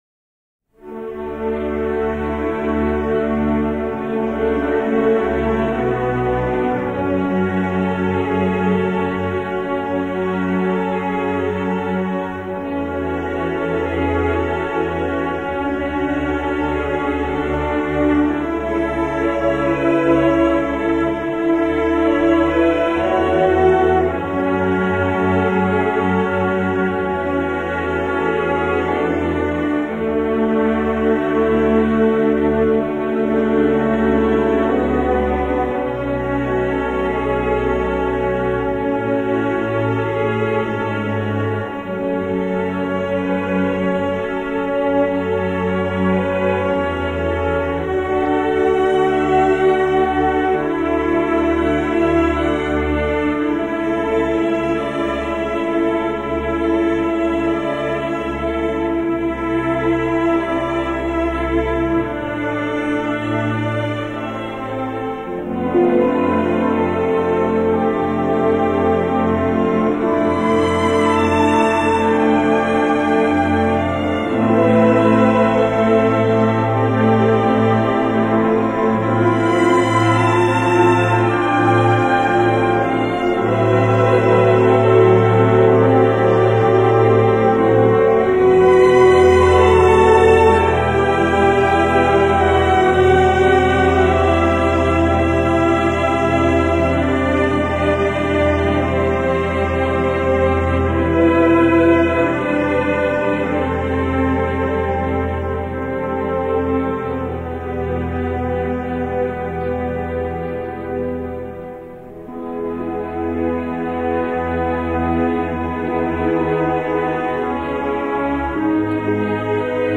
lyrique